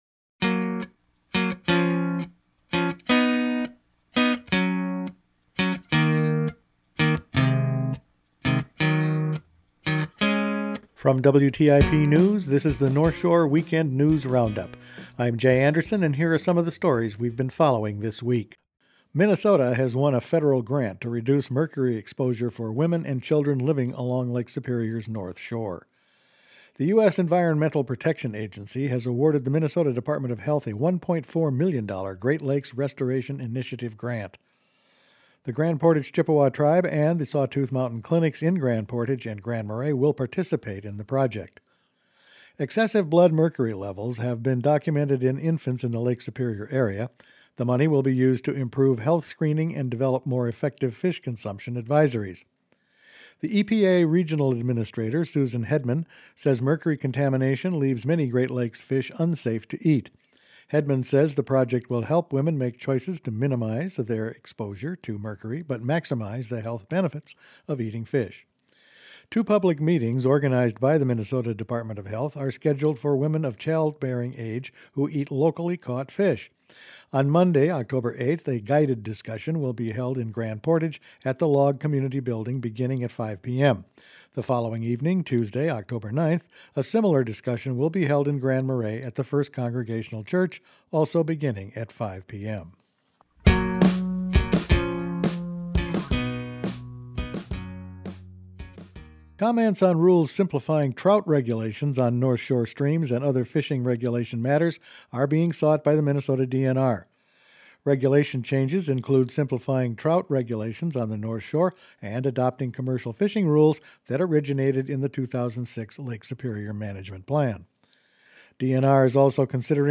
Weekend News Roundup for October 6